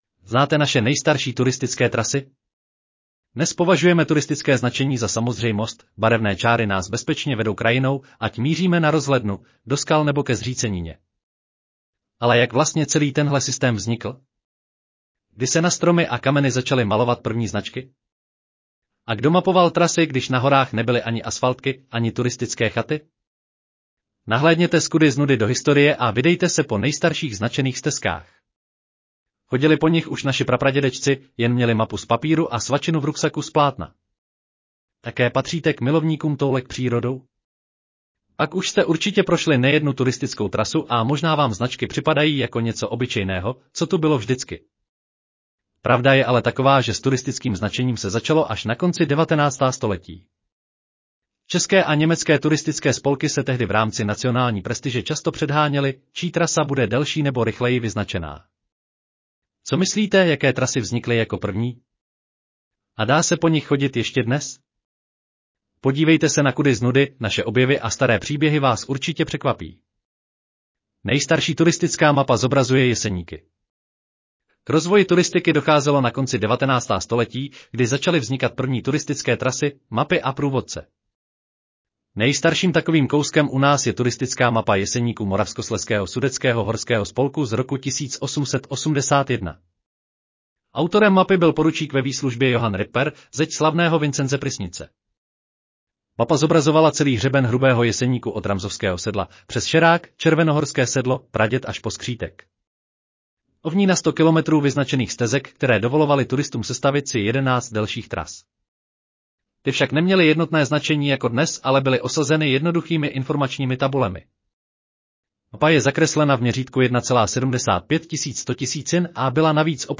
Audio verze článku Znáte naše nejstarší turistické trasy?